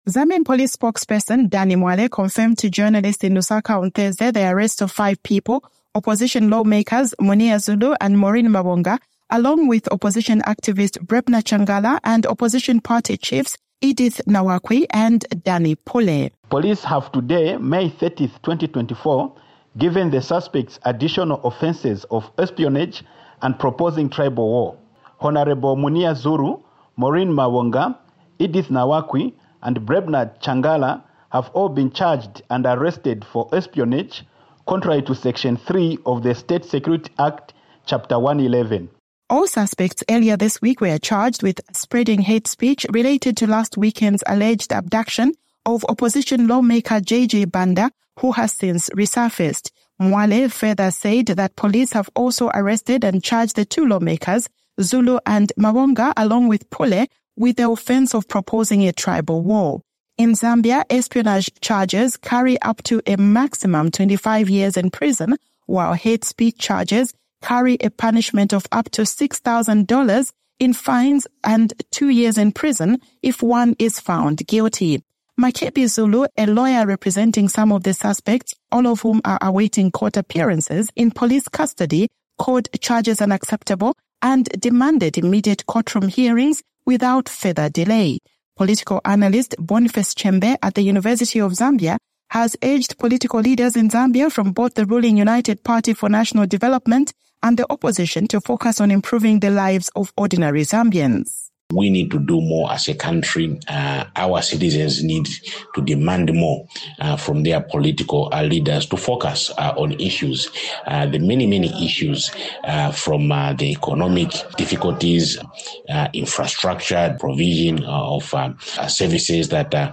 reports from Lusaka, Zambia